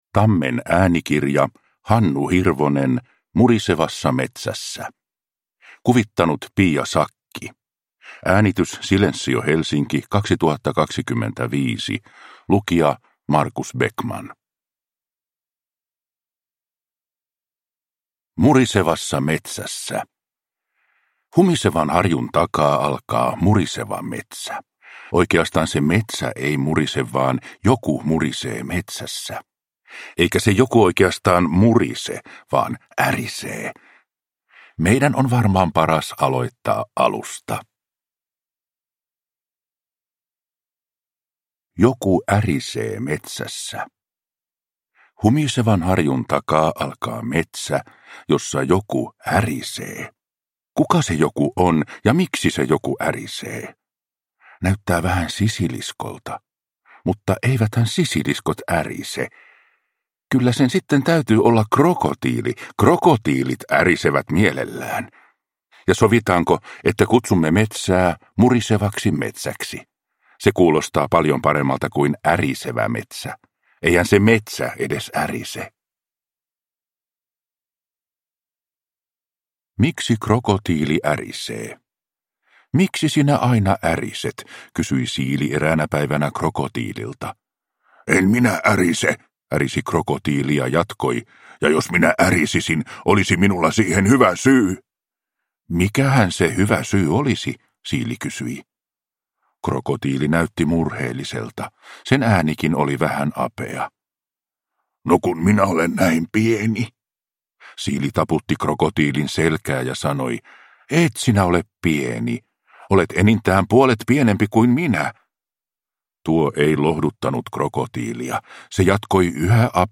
Murisevassa metsässä – Ljudbok